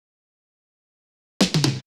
Fill 128 BPM (22).wav